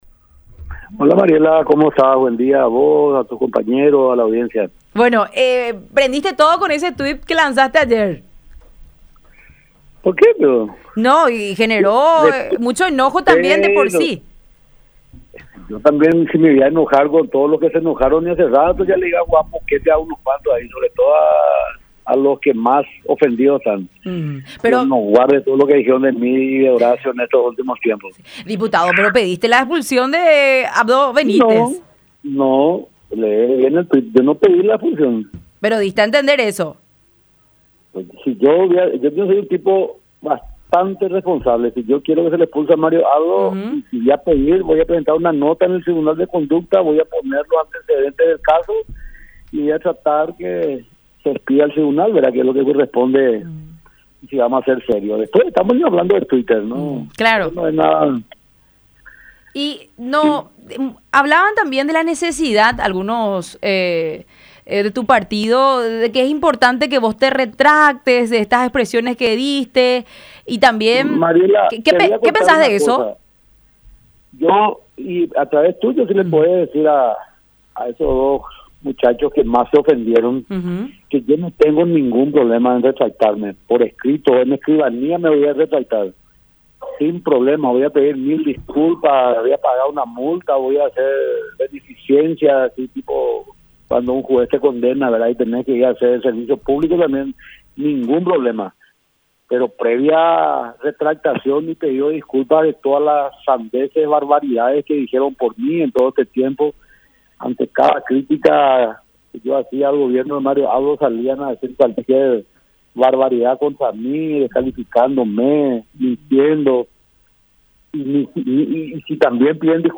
Walter Harms, diputado de la ANR.
“Yo soy muy responsable con estos casos. No. Yo no pedí que le expulsen a Mario Abdo. Si yo quiero que le expulsen a él, voy a presentar una nota en el Tribunal de Conducta, voy a poner los antecedentes del caso y voy a esperar a que se expida el tribunal”, dijo Harms en diálogo con La Unión Hace La Fuerza a través de Unión TV y radio La Unión.